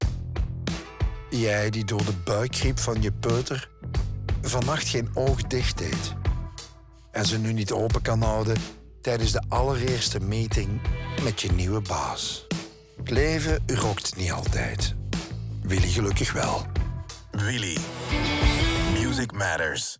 De audio in de geluidsstudio’s van DPG Media zelf
Radiospot Radio Willy Moe.wav